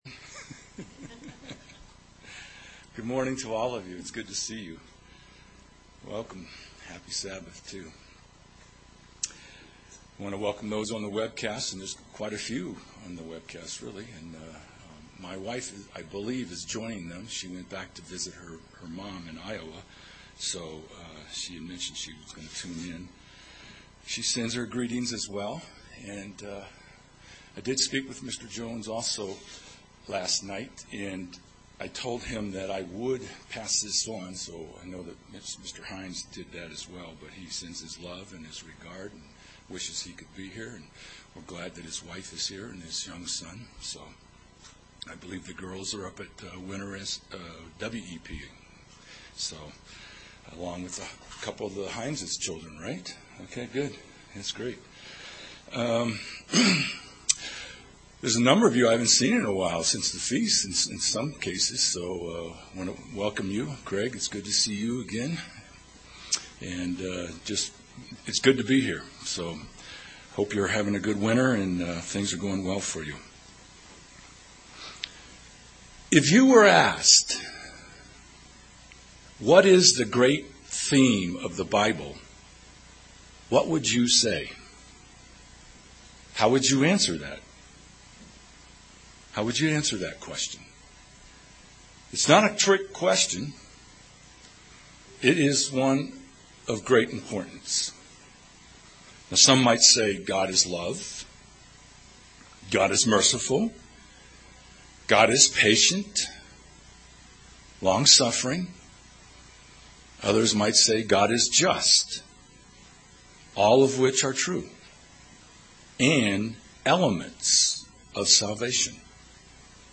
Can we know what God is doing? This sermon examines the greatest them in the bible.